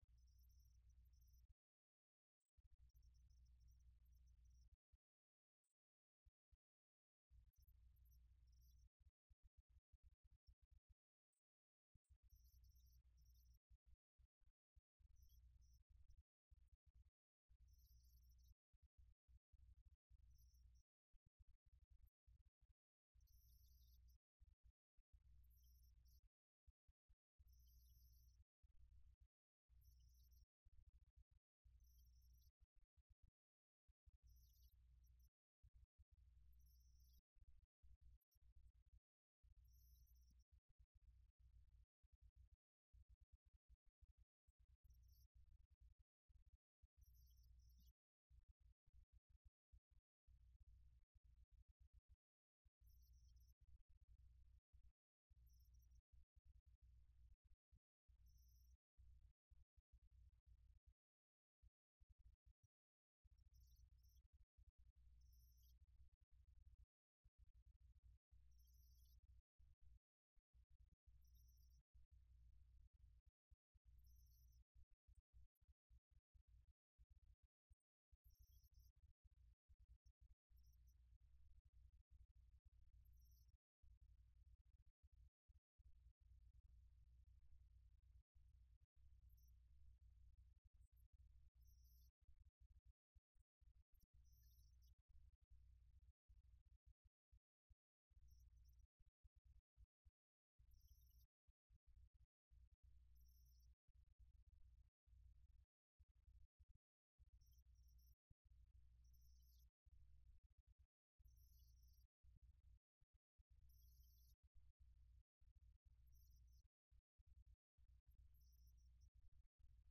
خطبة عيد الفطر- المدينة - الشيخ عبدالباريءالثبيتي 1-10-
تاريخ النشر ١ شوال ١٤٣٤ هـ المكان: المسجد النبوي الشيخ: فضيلة الشيخ عبدالباري الثبيتي فضيلة الشيخ عبدالباري الثبيتي خطبة عيد الفطر- المدينة - الشيخ عبدالباريءالثبيتي 1-10-